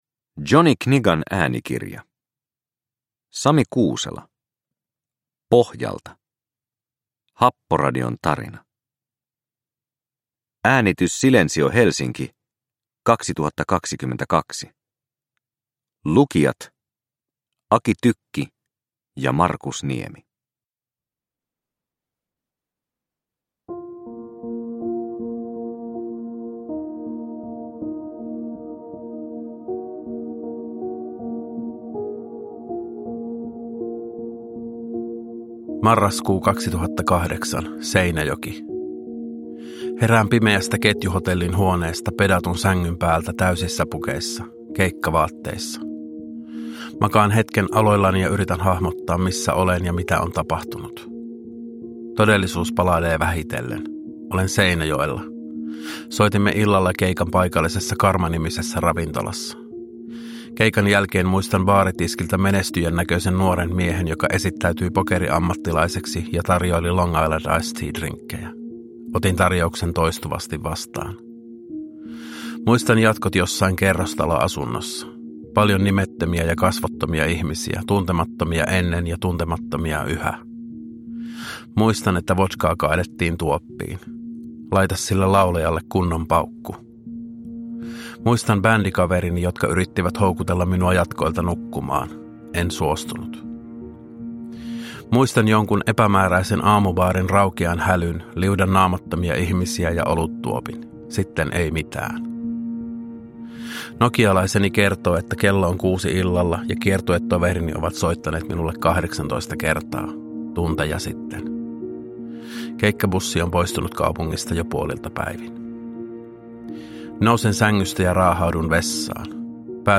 Pohjalta – Happoradion tarina – Ljudbok – Laddas ner
Äänikirja sisältää musiikkia - suosittelemme kuuntelemaan sen normaalinopeudella.
Äänikirjan äänimaiseman on suunnitellut ja säveltänyt Aki Tykki.